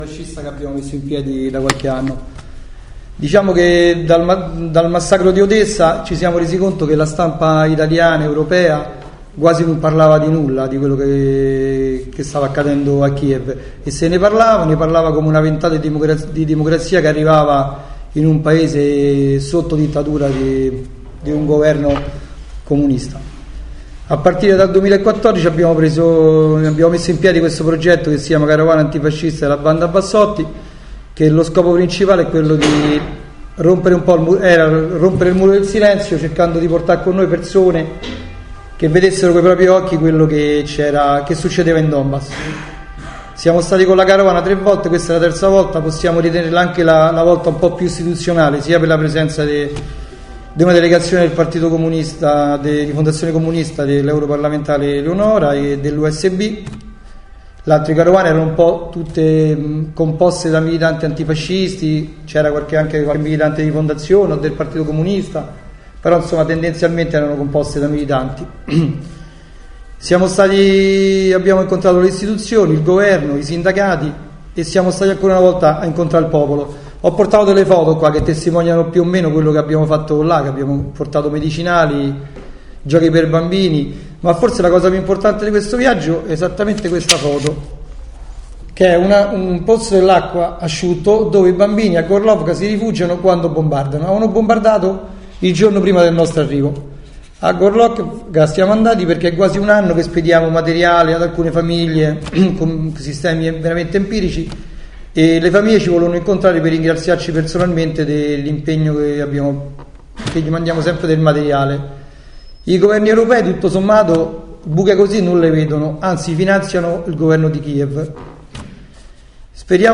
Conferenza stampa